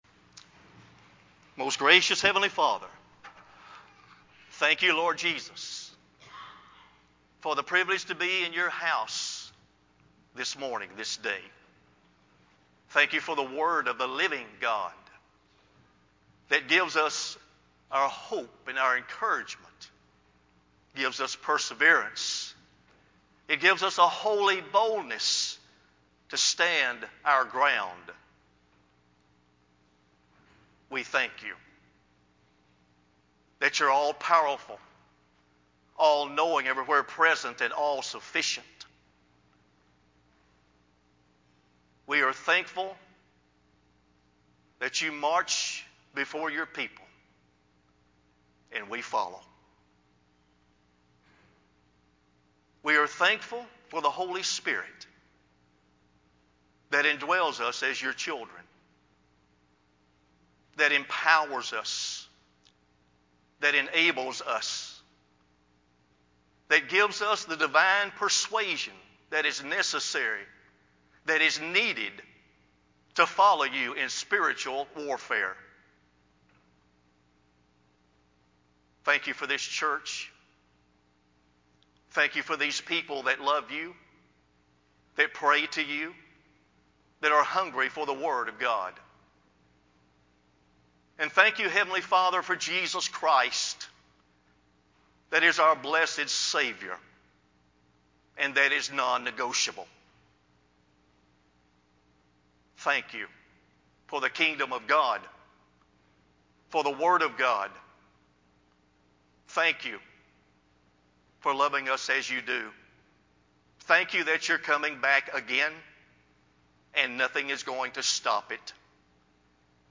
sermon-11-22-CD.mp3